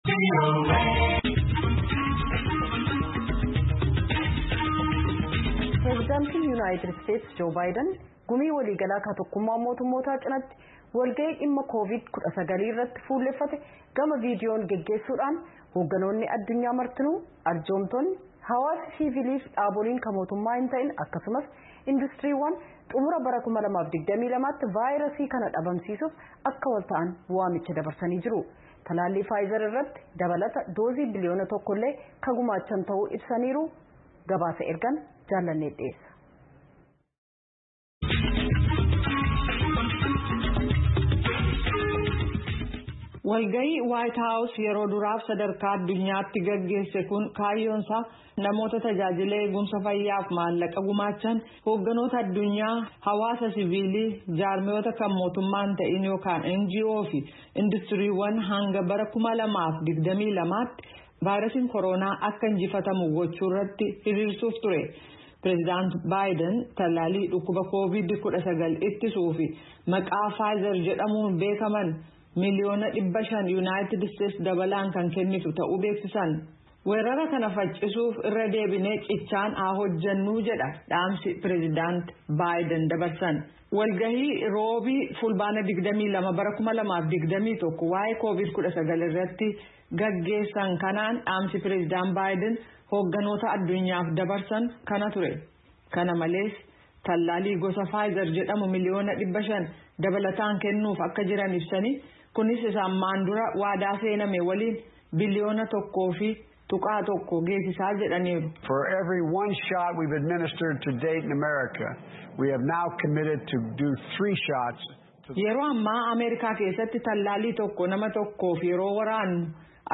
Gabaasa